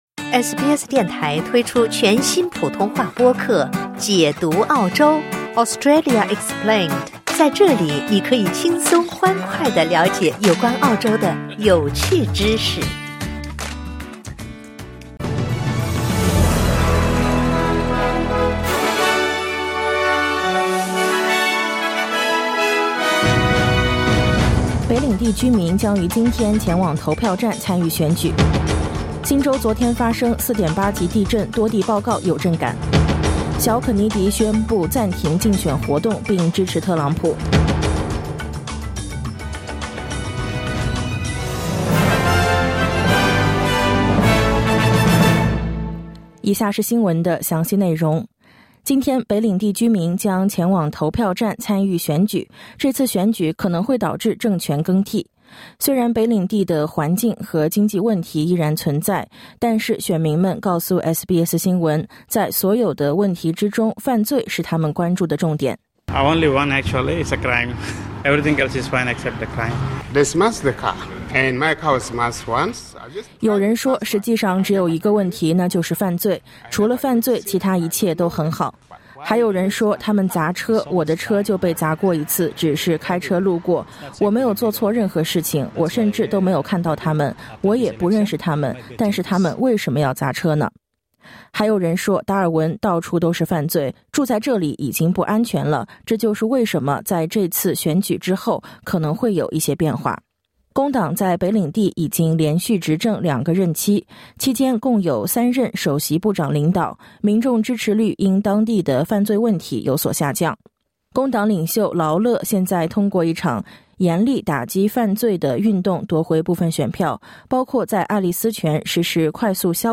SBS早新闻（2024年8月24日）